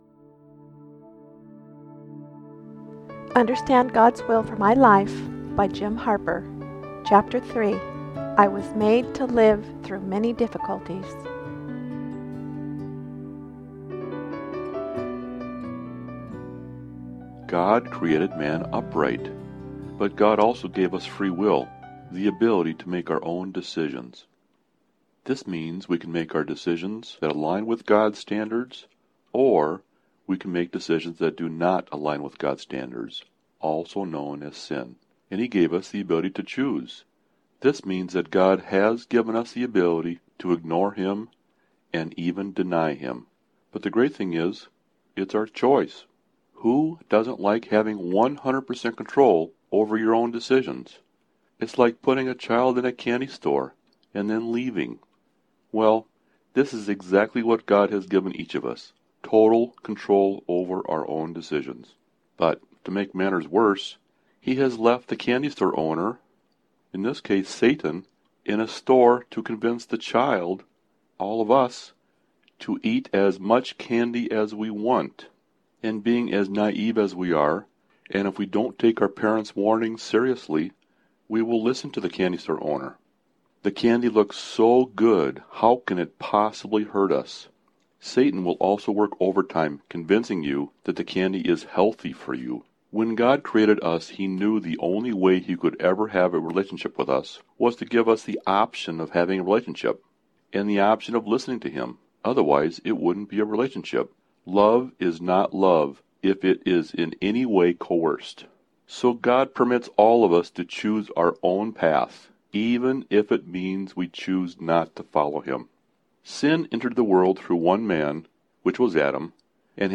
Audiobook-Understand-Gods-Will-Chapter3.mp3